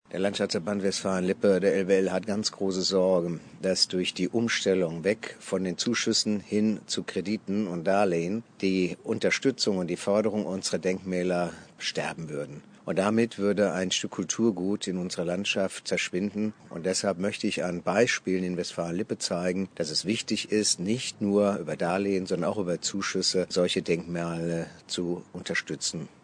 Unterhalb dieser Pressemitteilung finden Sie einen O-Ton vom LWL-Direktor Dr. Wolfgang Kirsch.